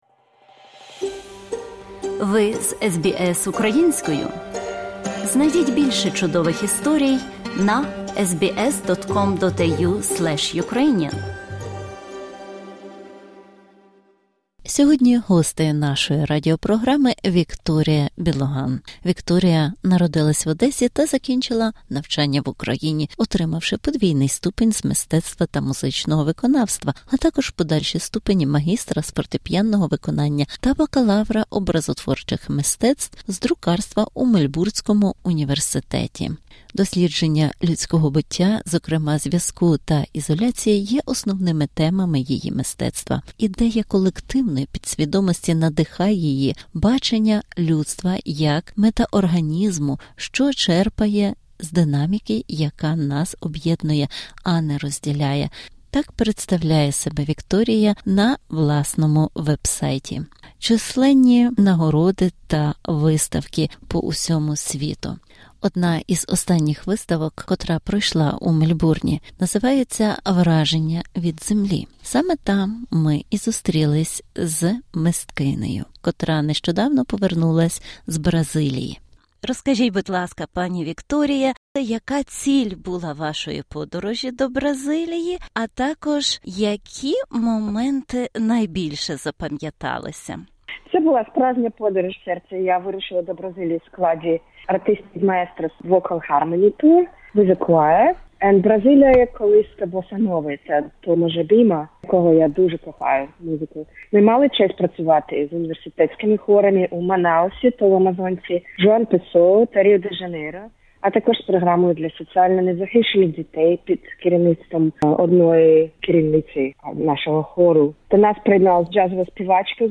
У цьому інтерв'ю